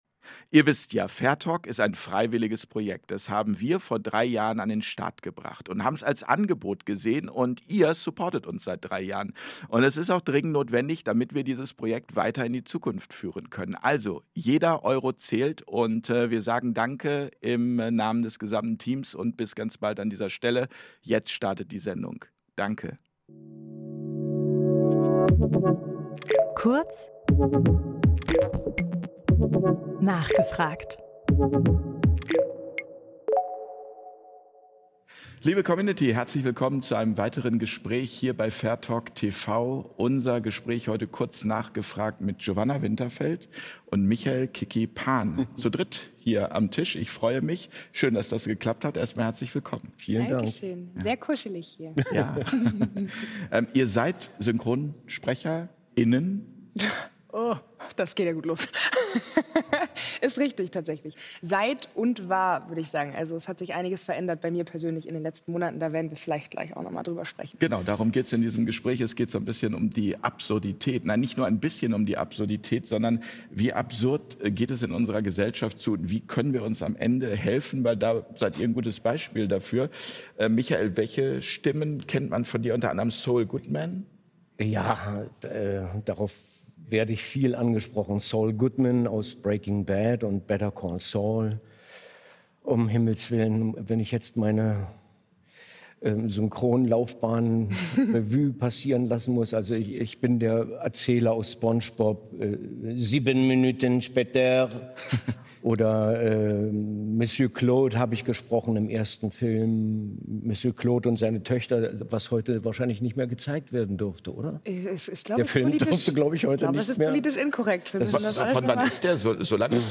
Das Interview Format